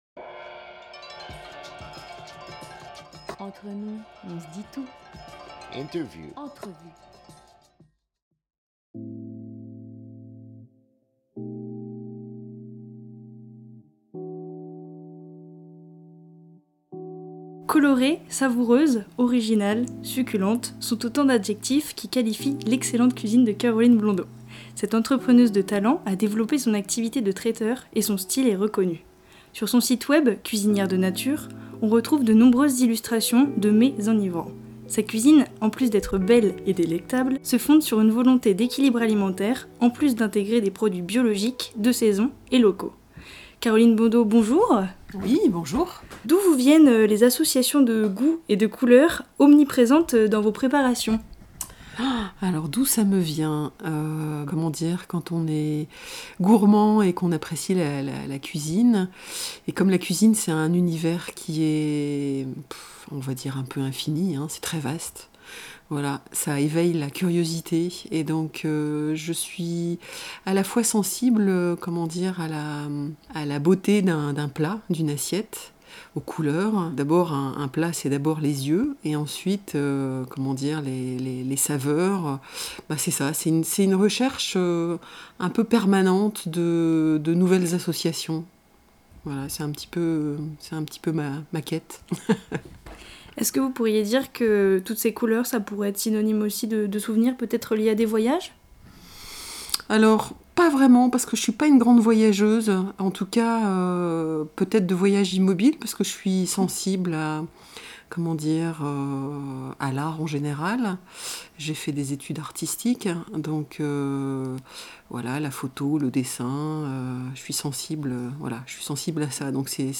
25 janvier 2021 20:38 | Interview